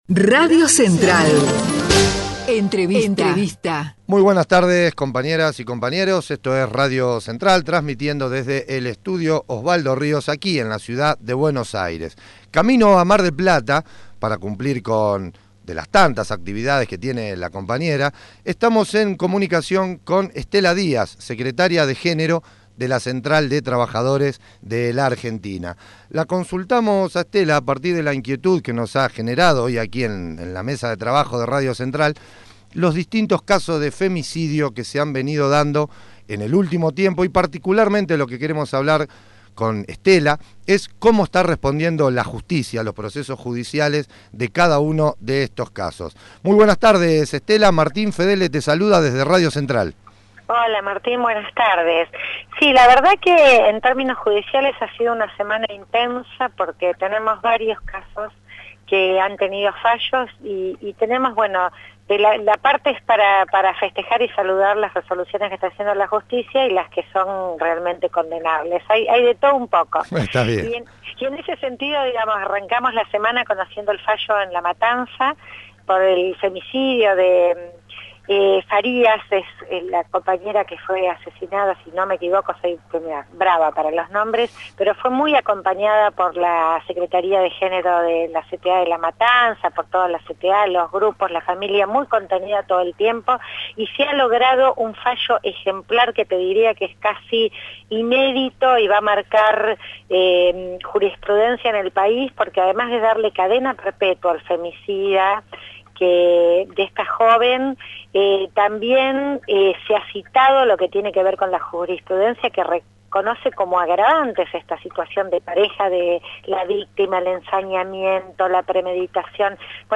ESTELA DIAZ - SECRETARIA GÉNERO CTA (entrevista RADIO CENTRAL)